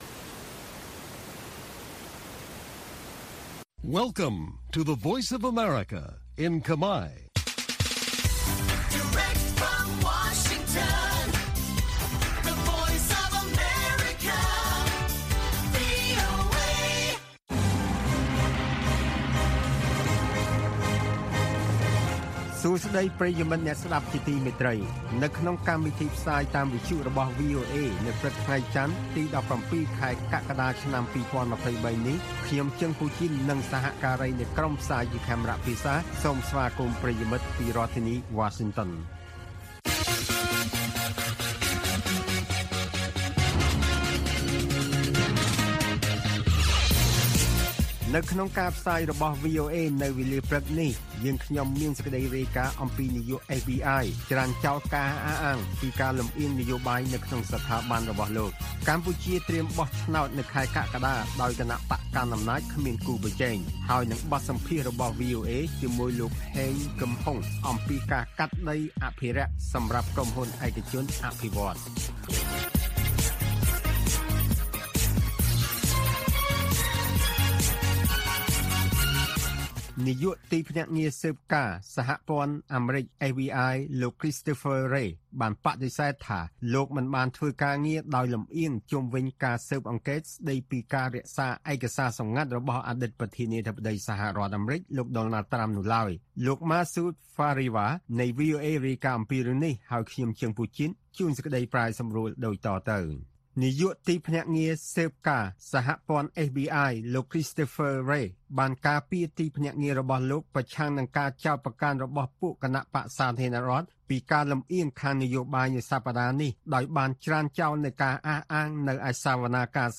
ព័ត៌មានពេលព្រឹក
បទសម្ភាសន៍ VOA